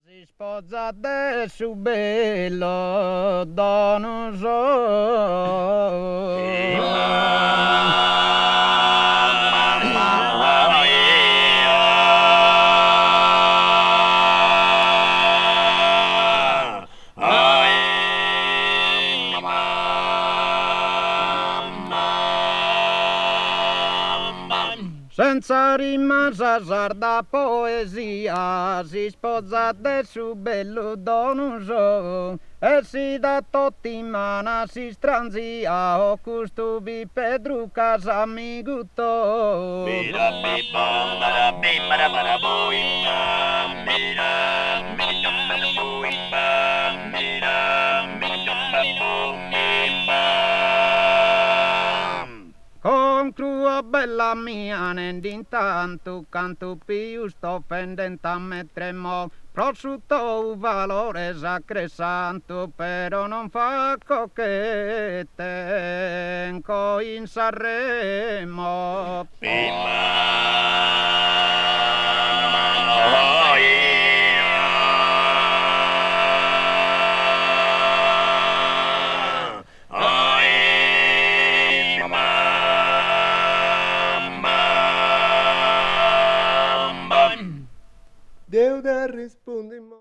avant-jazz   contemporary jazz   ethnic jazz   free jazz